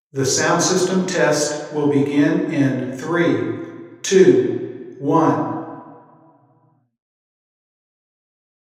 The following equipment was used for the collection of room impulse responses using log sine sweeps.
Microphone: Sennheiser Ambeo
The Chapel is a smaller space with high ceiling and classical design. It is intentionally acoustically “live” with a mid-band reverberation time of about 1.5 sec. Here are the measures collected 40 ft from an omni source.